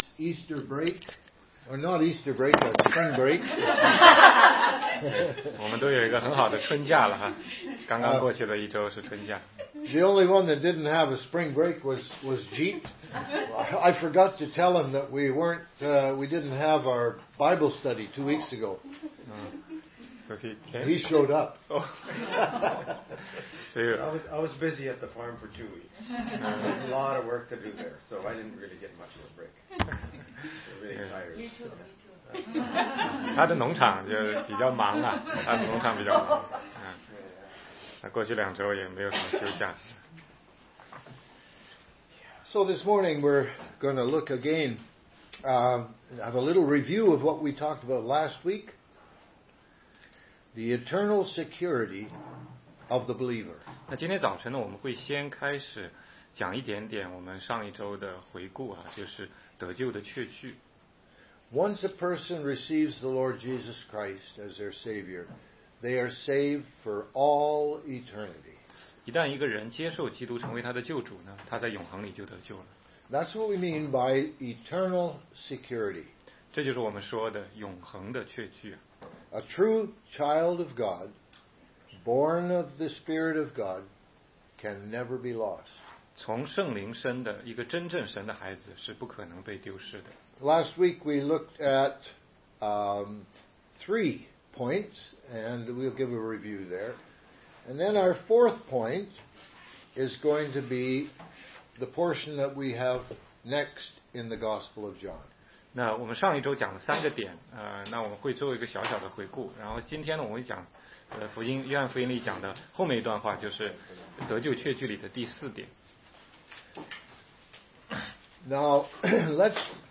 16街讲道录音 - 约翰福音10章救恩永不会失去系列之二